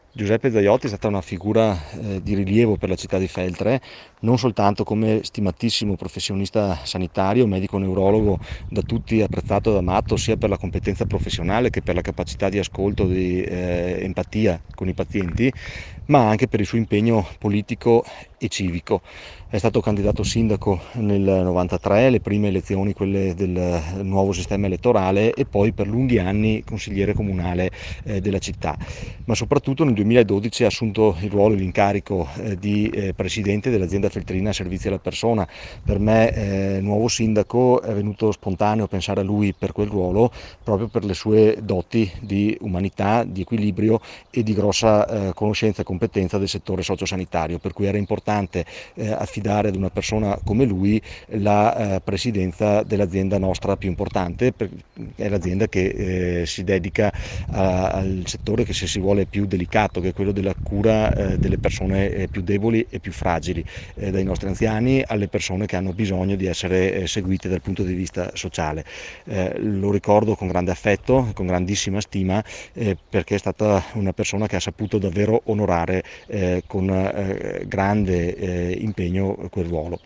IL SINDACO DI FELTRE PAOLO PERENZIN